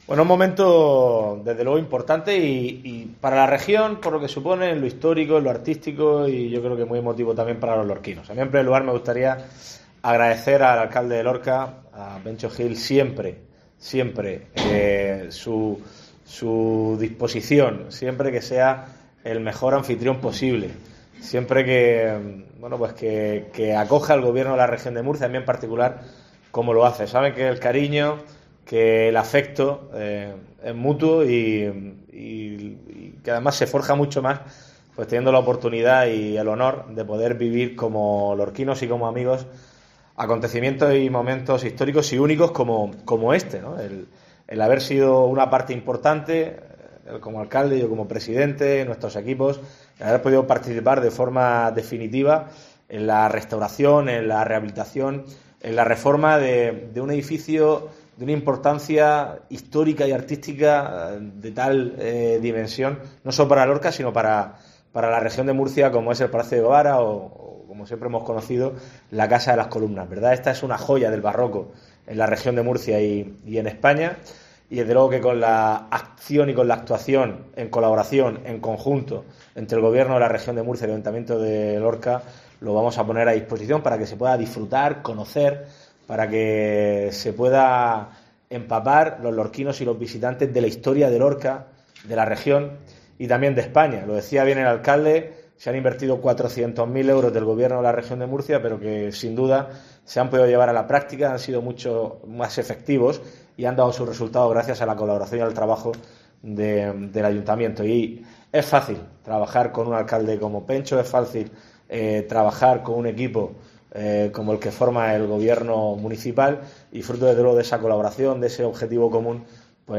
Fernando López Miras, presidente CARM